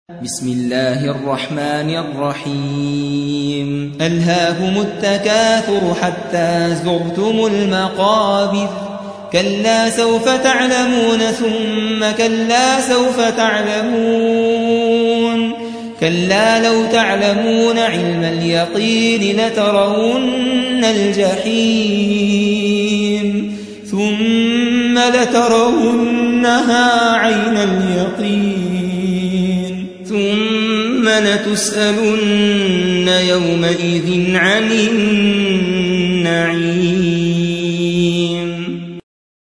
102. سورة التكاثر / القارئ